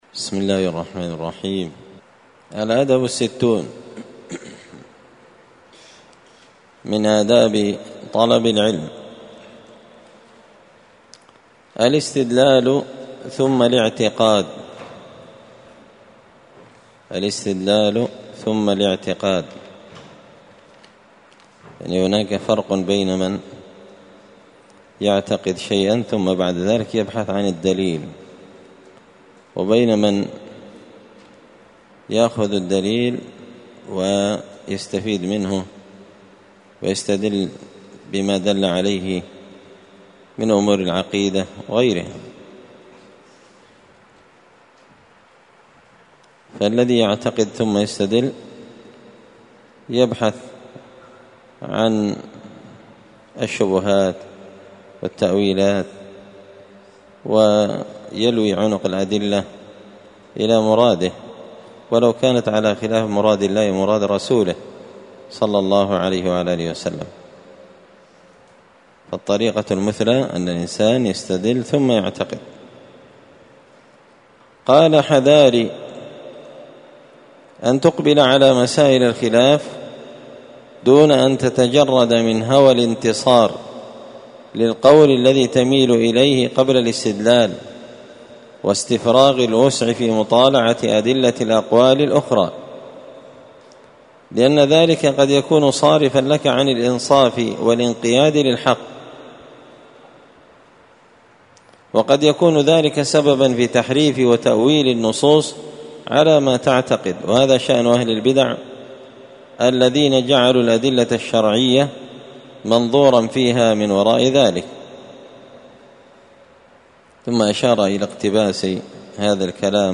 الأثنين 15 ذو الحجة 1444 هــــ | الدروس، النبذ في آداب طالب العلم، دروس الآداب | شارك بتعليقك | 13 المشاهدات